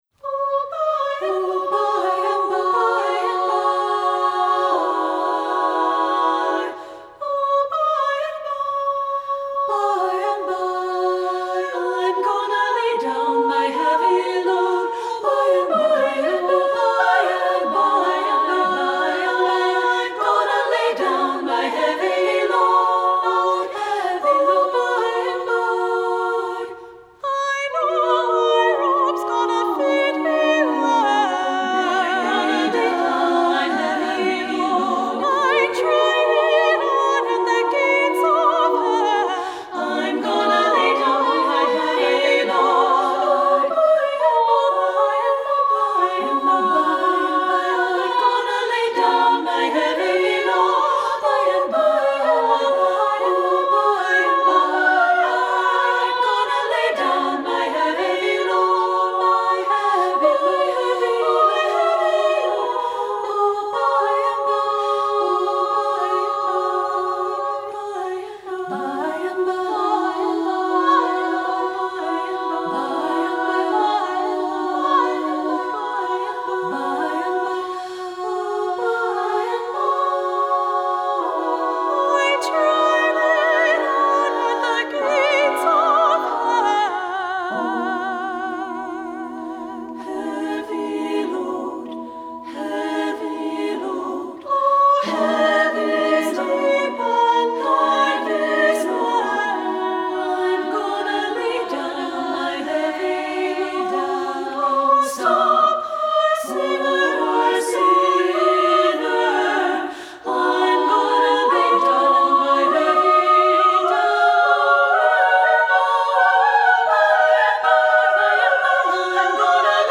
Composer: African-American Spiritual
Voicing: SSA divisi a cappella